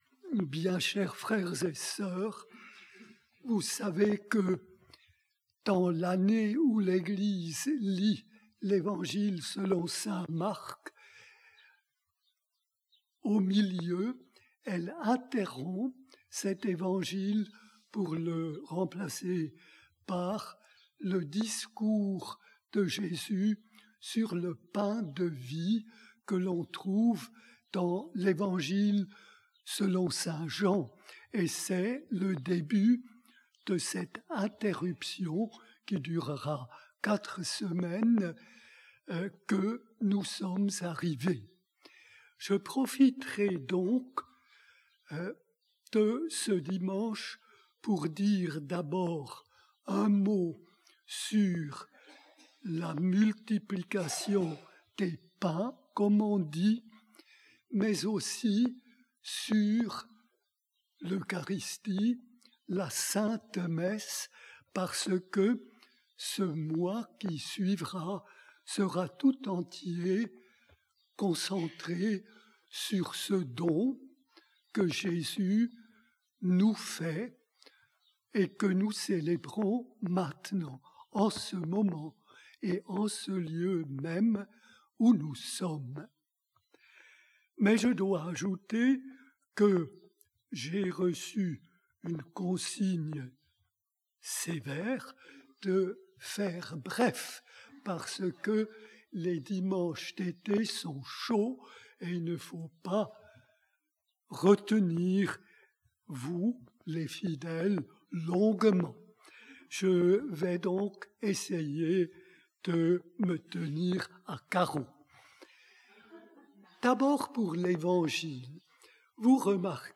Pour découvrir son homllie, nous vous invitons à écouter un enregistrement en direct.